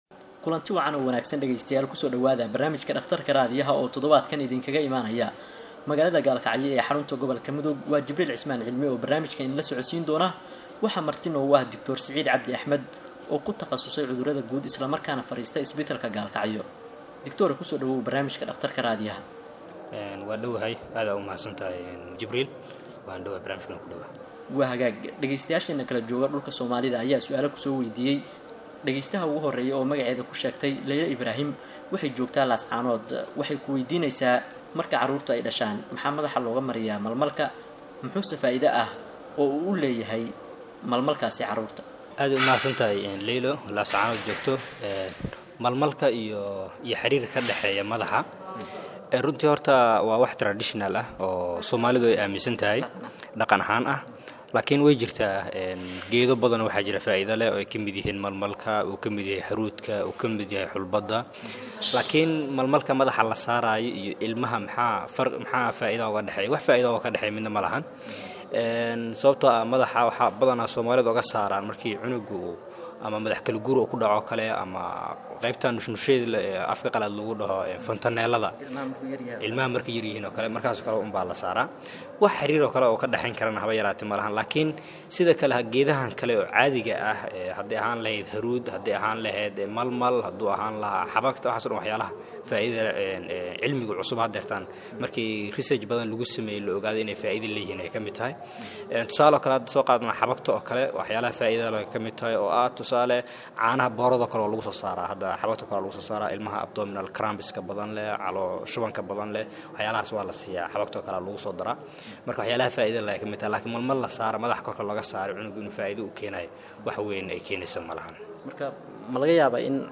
Waa baraamij ay dhegeystayaasha idaacadda Ergo ay su’aalo caafimaad ku soo waydiiyaan dhaqaatiir kala duwan oo jooga dalka gudihiisa.